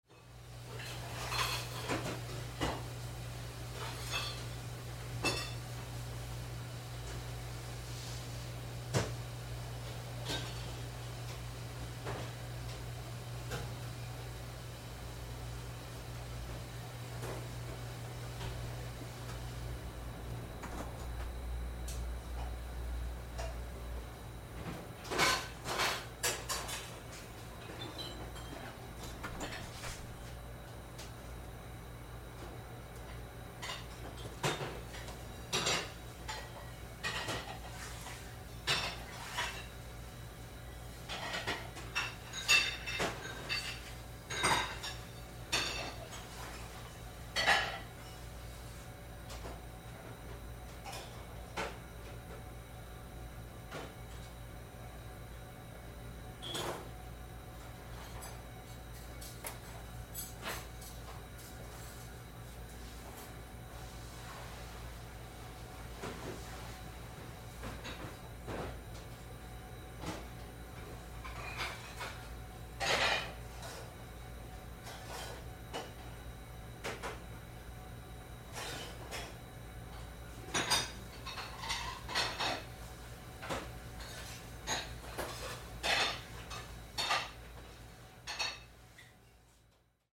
厨房-生活环境-图秀网
图秀网厨房频道，提供厨房音频素材。